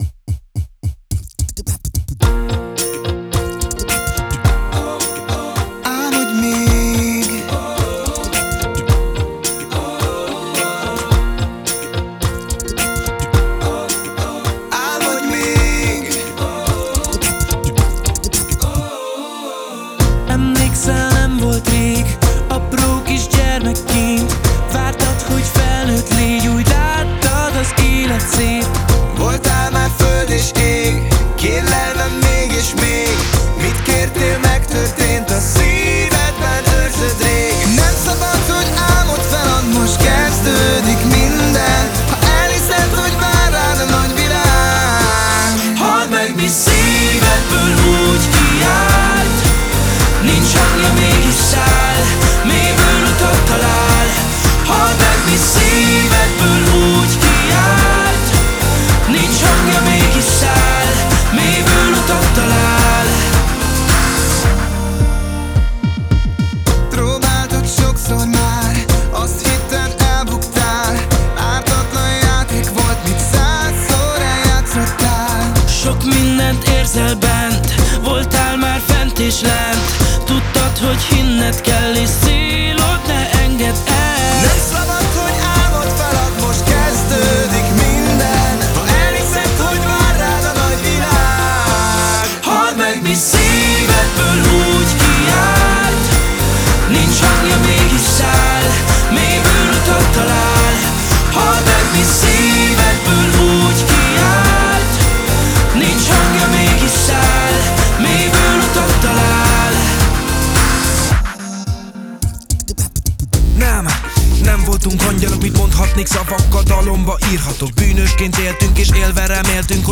boyband